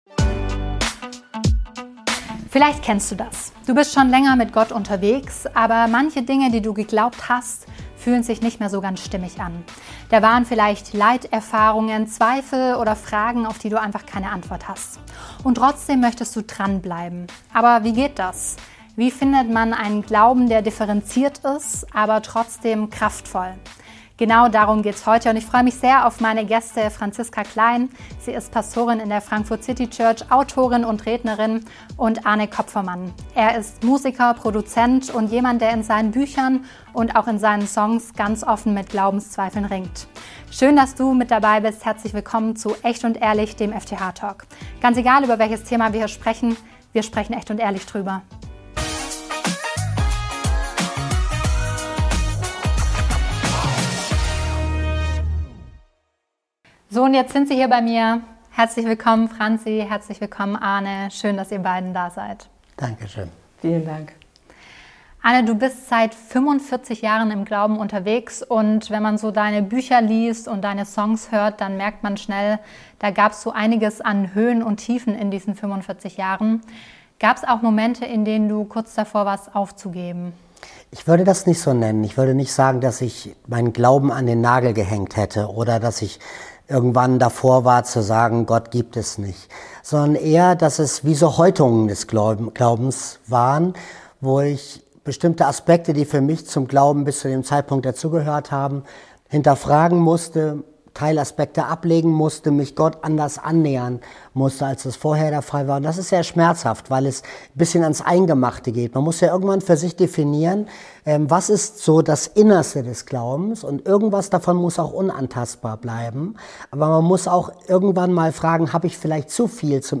Ein Talk über Zweifel, Freiheit und einen Glauben, der wirklich tragfähig ist.